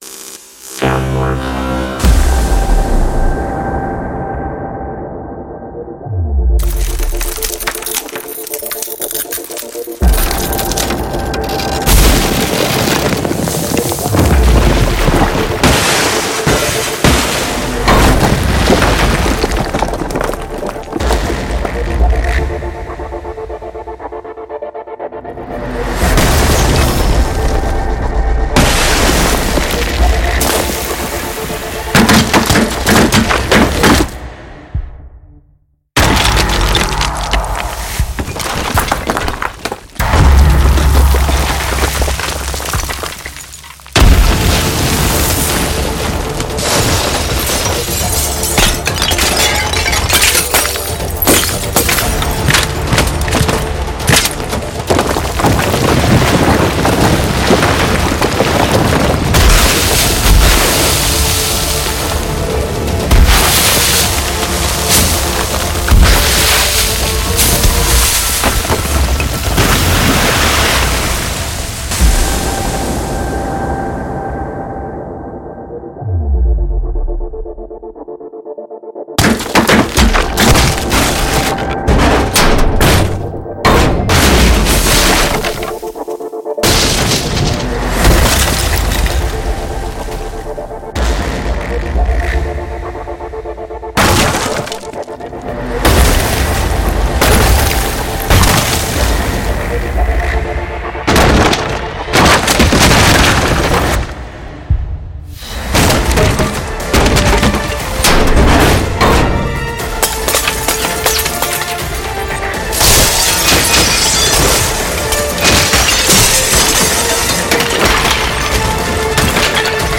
【材料破裂/撞击/电影FX风格采样包】Rupture – Soundpack Preview
超过20种不同的材料破裂了！
–砖块破碎/压碎
–汽车撞击
–玻璃碎片，碎裂，破裂
–冰爆炸
–金属撞击
–水溅
–木材压碎，撞击，断裂